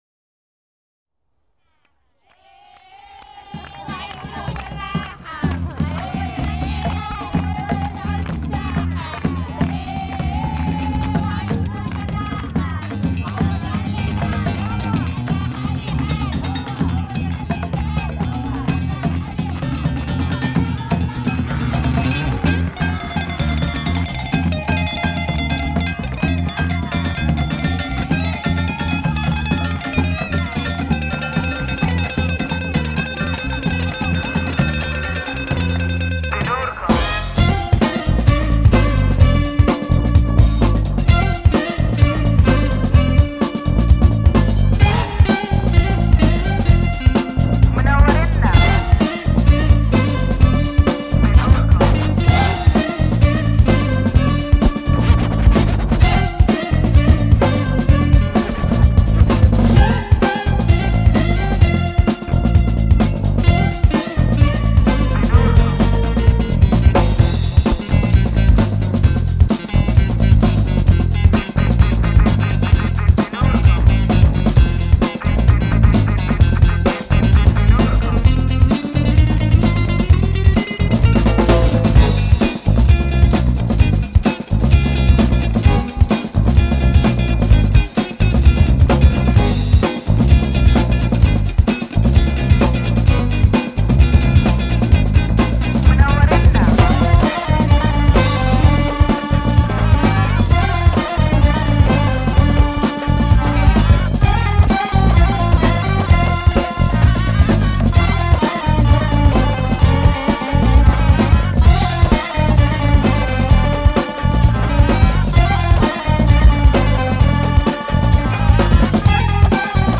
this was my offering from a jam